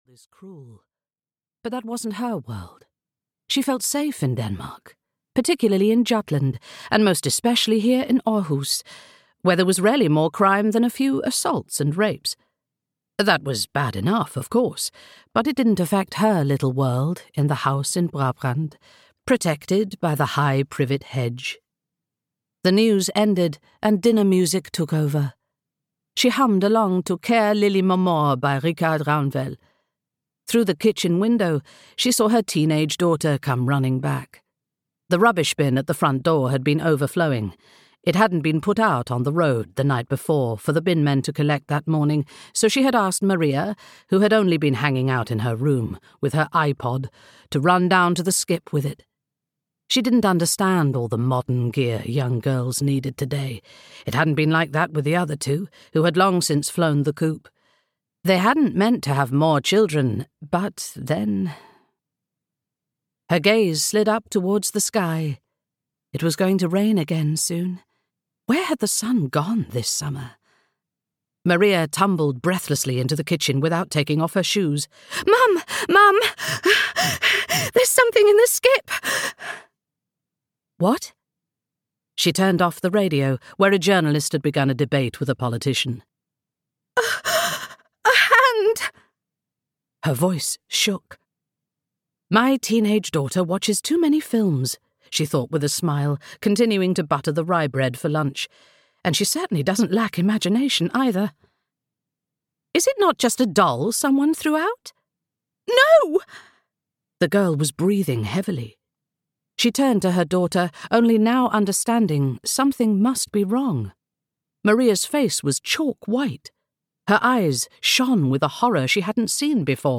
Audio knihaUsername: Doll Child (EN)
Ukázka z knihy